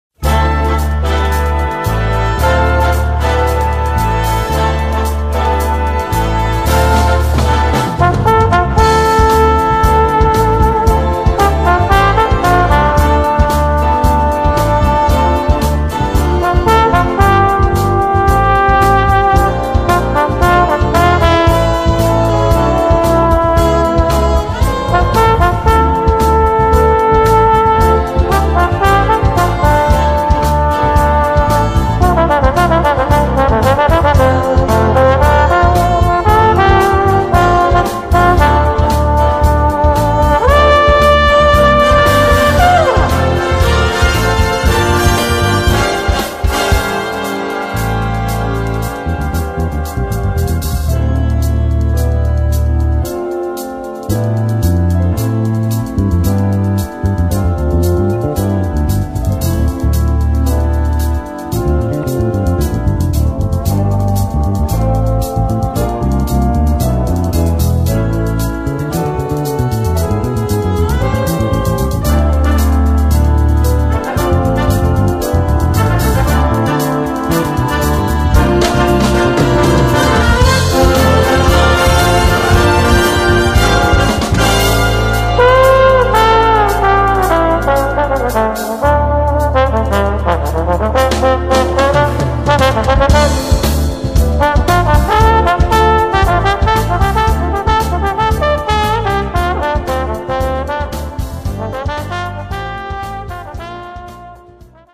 Voicing: Viola and Brass Band